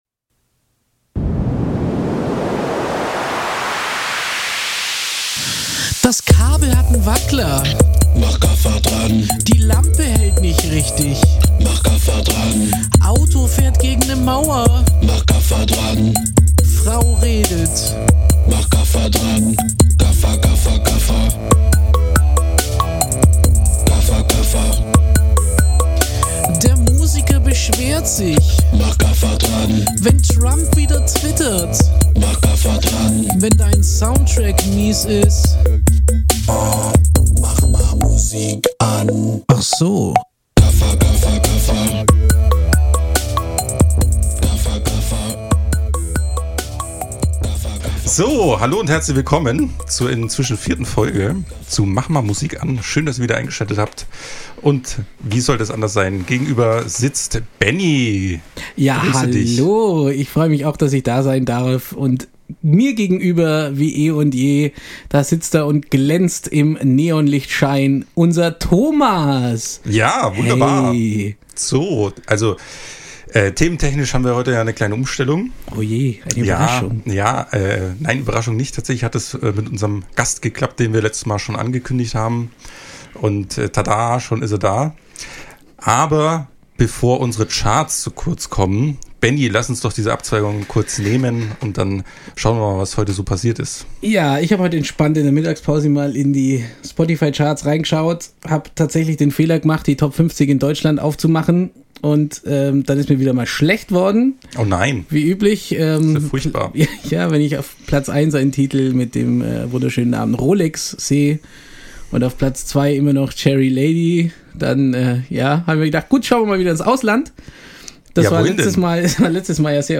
Heute begrüßen wir unseren ersten Gast, direkt aus dem Veranstaltungs-Business und den Bühnen dieser Welt! Er kennt sie alle und er erzählt davon!